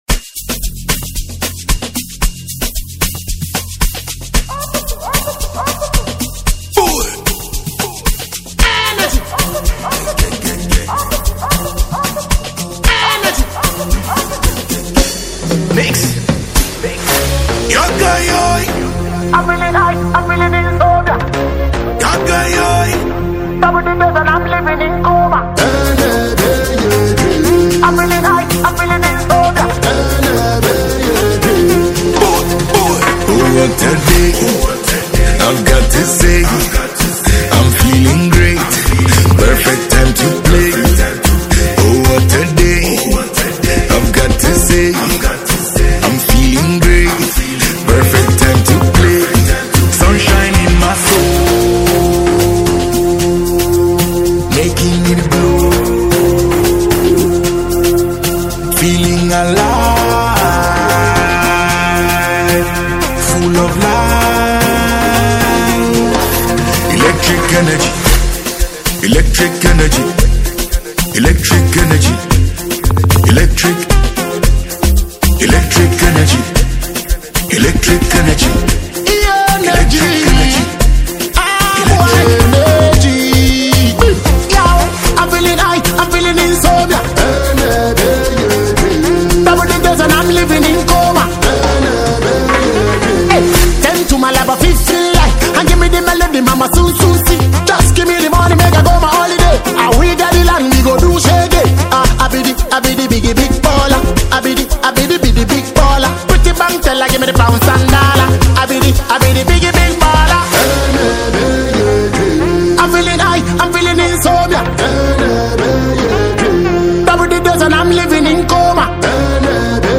This is a true afrobeat reggae dancehall hit song.